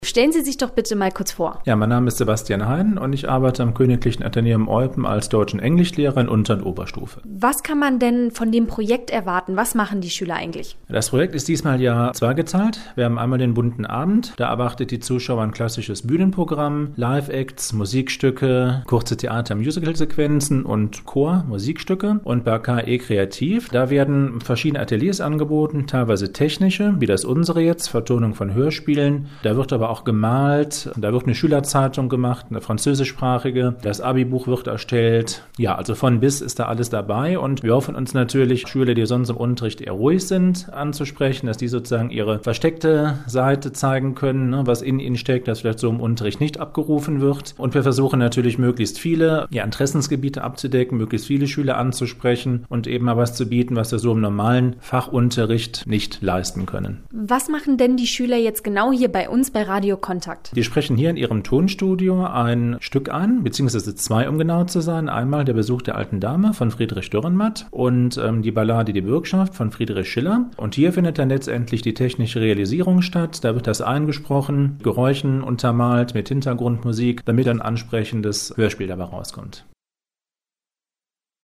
Einer der beiden begleitenden Lehrer kam ebenfalls zu Wort: